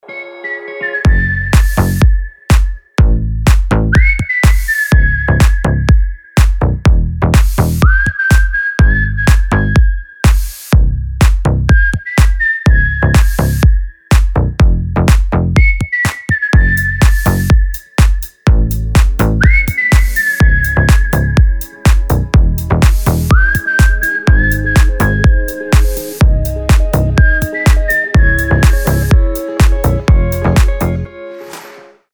Свист, гитара и басы - рингтон